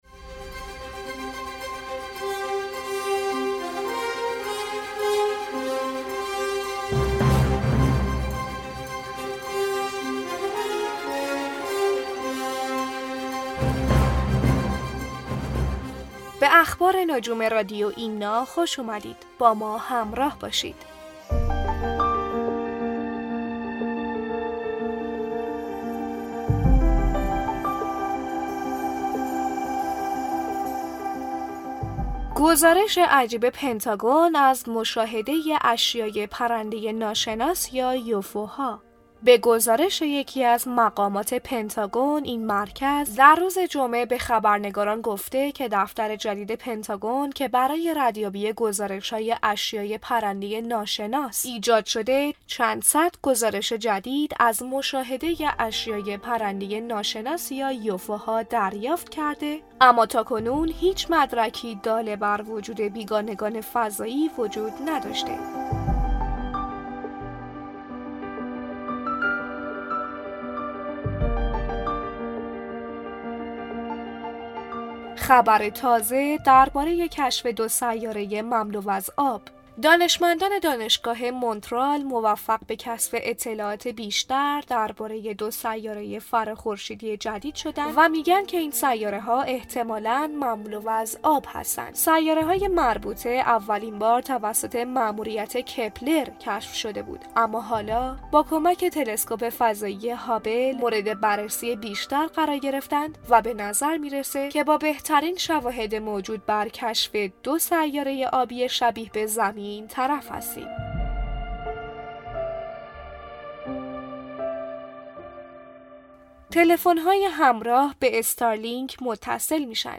بسته خبری نجوم رادیو ایمنا/